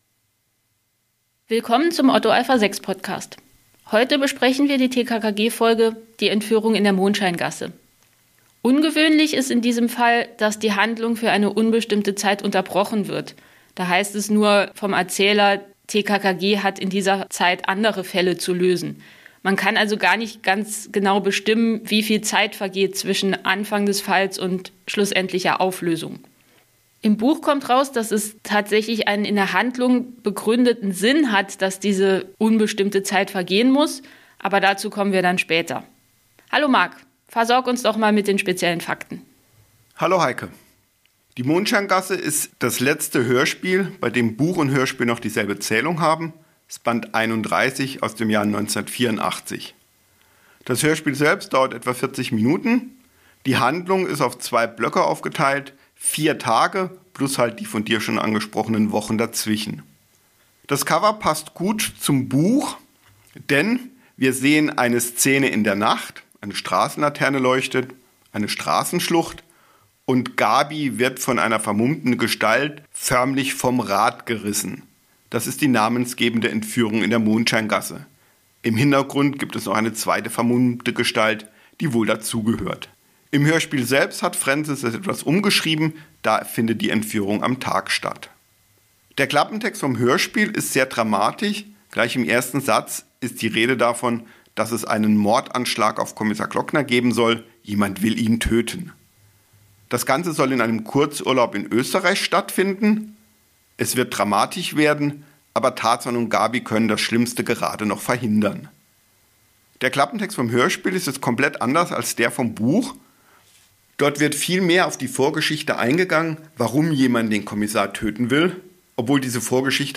oa6x33 Tkkg - Die Entführung in der Mondscheingasse (Hörspielbesprechung) ~ otto-alpha-6 Podcast